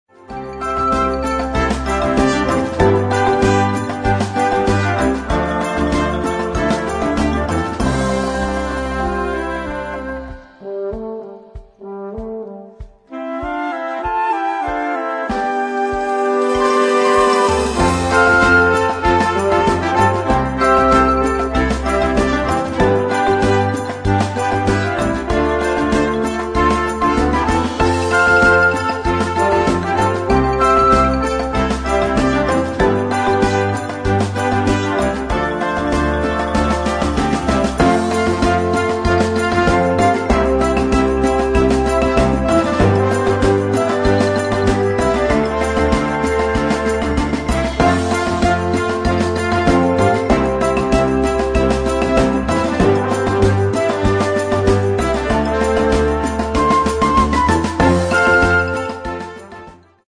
Noten für flexibles Jugend Ensemble, 4-stimmig + Percussion.